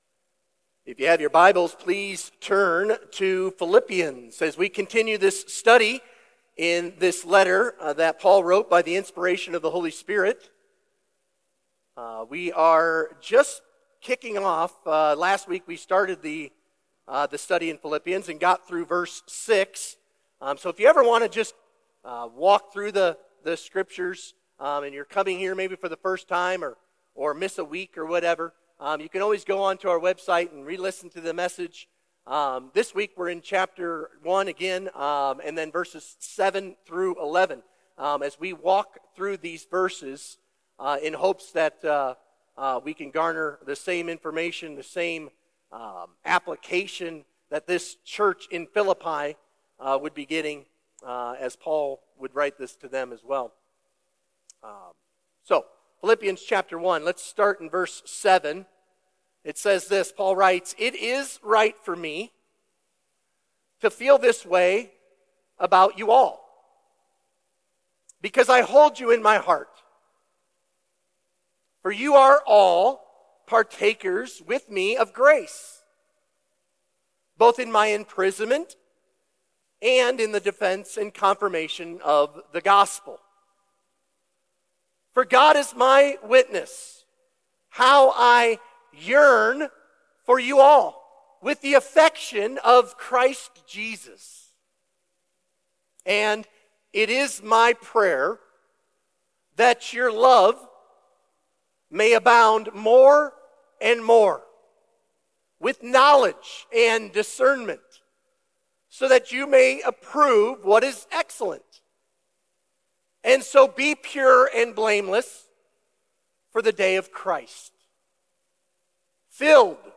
Sermon Questions What happens when a church is doctrinally sound but is not loving?